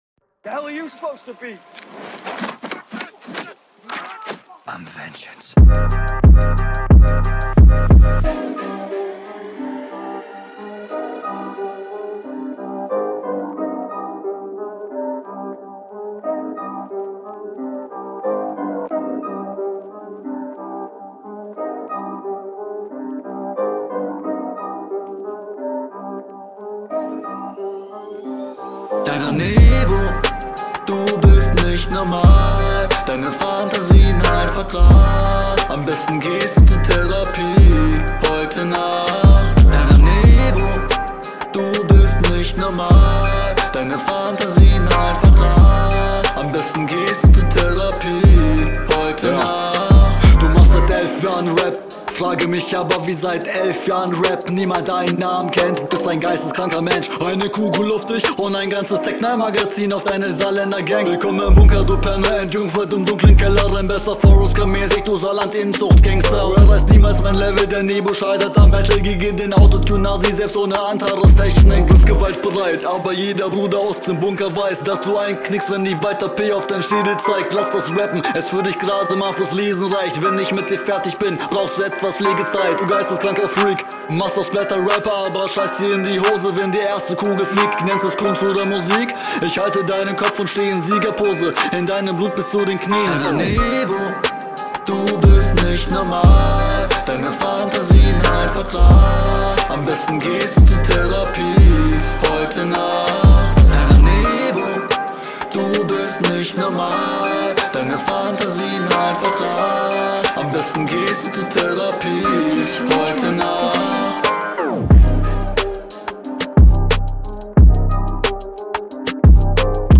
Beat ist sehr geil.
beat einstieg bockt schon mal gut! soundbild find ich chillig, stimme versinkt schön im hall …